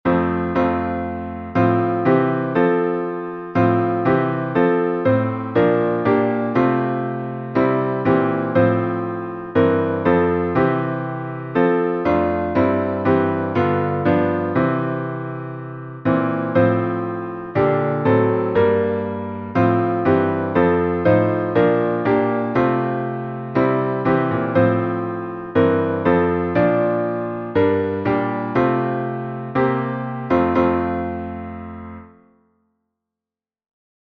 Downloads Áudio Áudio cantado (MP3) Áudio instrumental (MP3) Áudio intrumental (MIDI) Partitura Partitura 4 vozes (PDF) Cifra Cifra (PDF) Cifra editável (Chord Pro) Mais opções Página de downloads
salmo_24B_instrumental.mp3